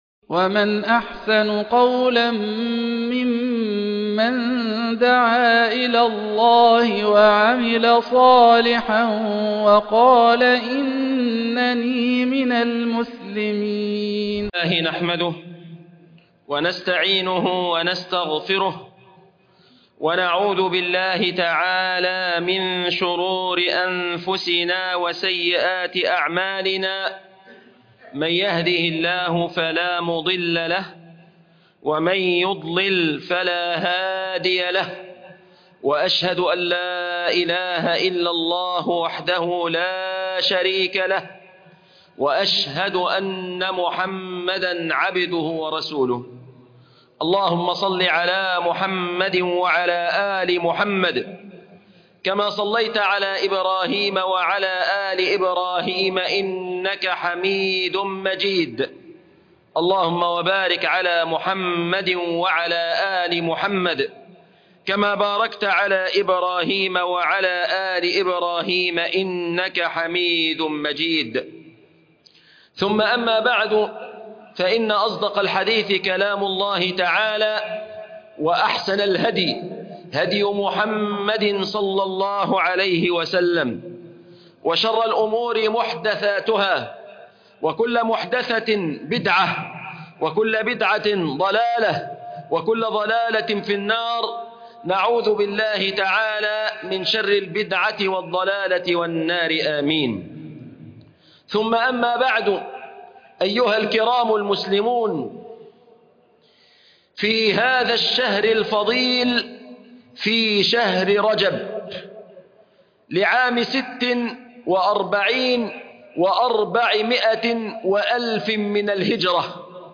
( آيات الرحمن في الظالمين ) خطبة الجمعة